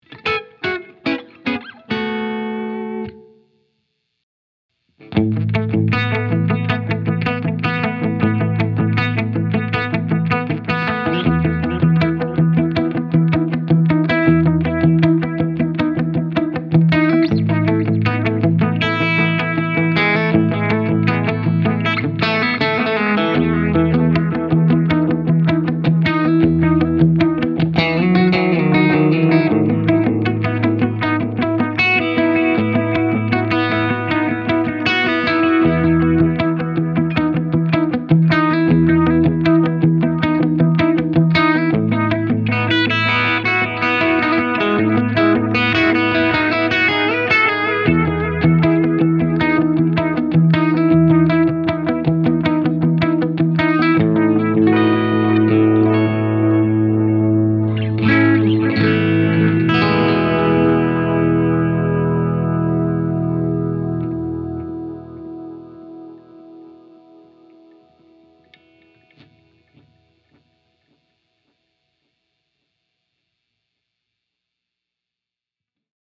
The warm-sounding repeats work well with the plucky guitar sound, adding weight but not swamping the notes.